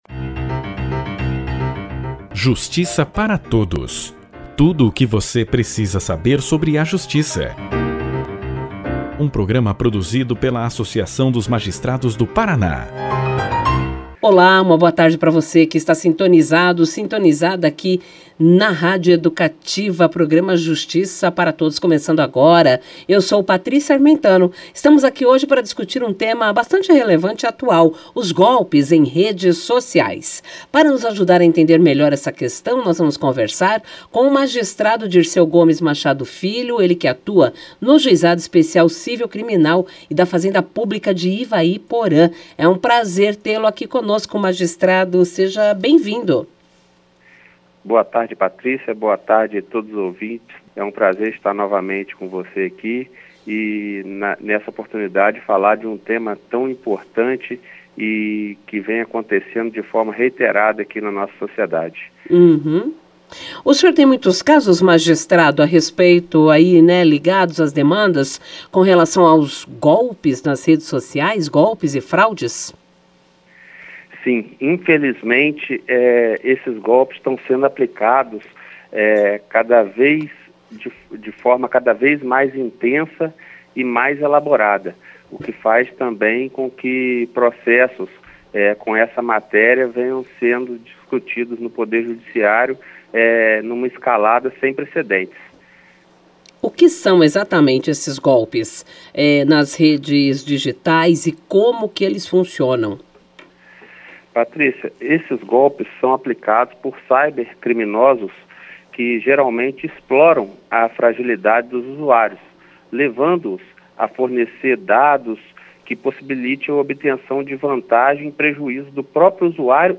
Juiz Titular do Juizado Especial Cível, Criminal e da Fazenda Pública de Ivaiporã, Dirceu Gomes Machado Filho participou do programa de rádio da AMAPAR e alertou a população sobre a aplicação de golpes nas redes sociais.